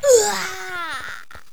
genie_die2.wav